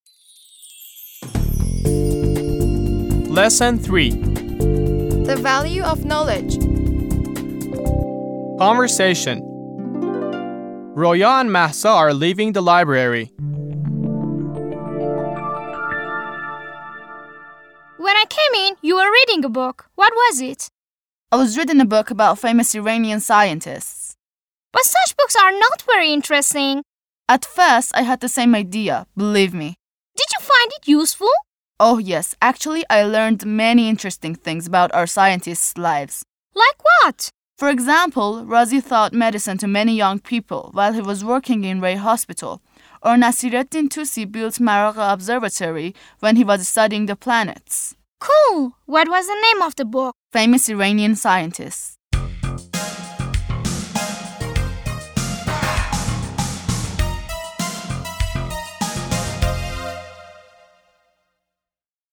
01-Lesson-3-(10)-Conversation.mp3